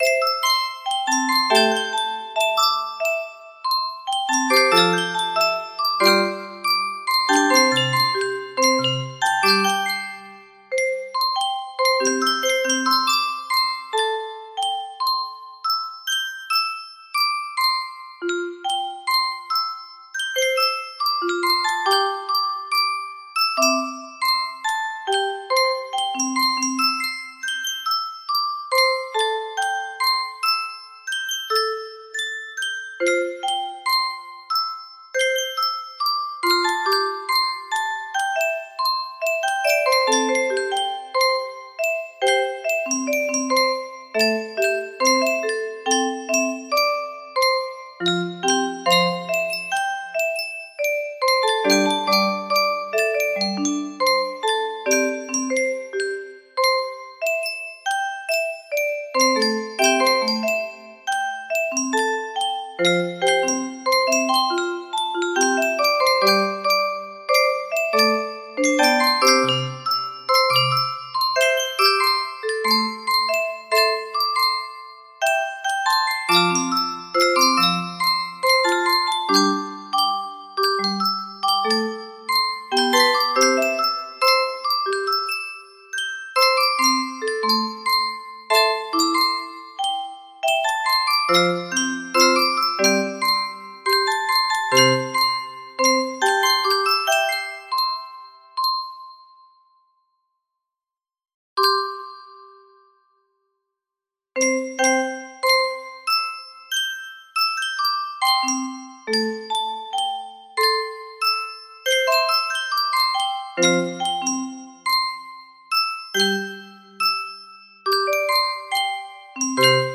Full range 60
Traditional Japanese stringed musical instrument